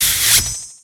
Grito de Pawniard.ogg
Grito_de_Pawniard.ogg